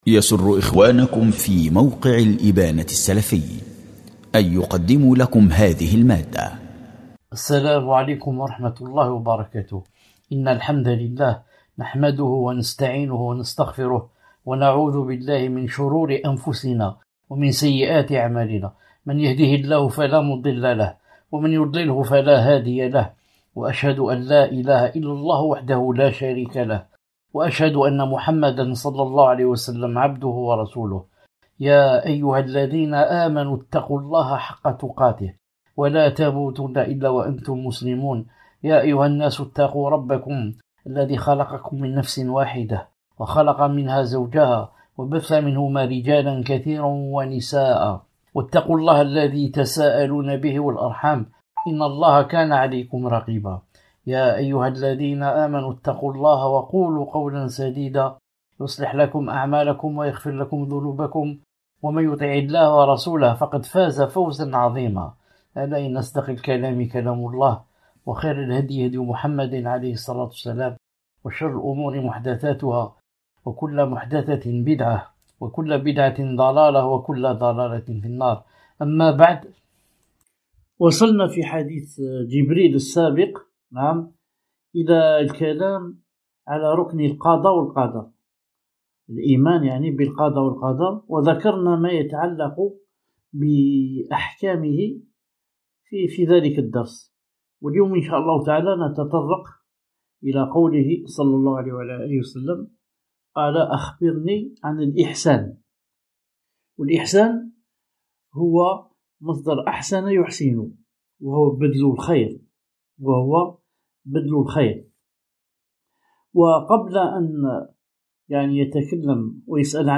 شرح الأربعون النووية الدرس 9